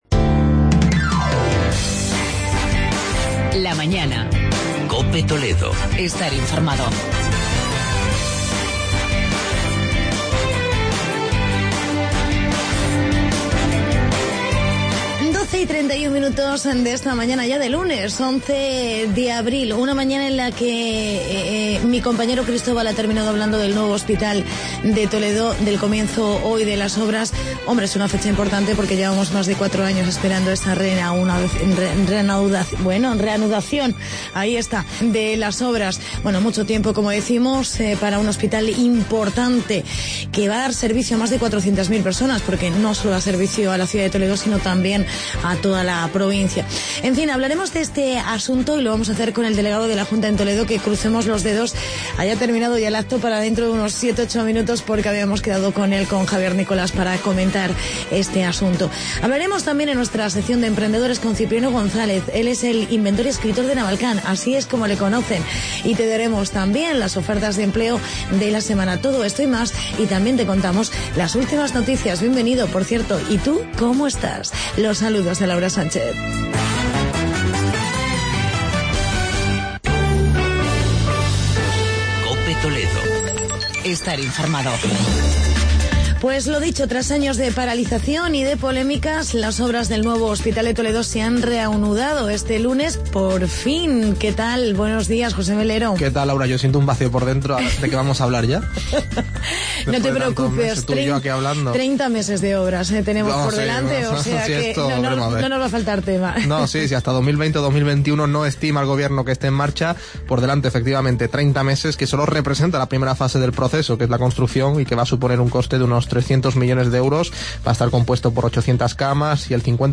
Entrevista con Javier Nicolás, delegado Junta Toledo por el Hospital de Toledo y en "Emprendedores" hablamos con...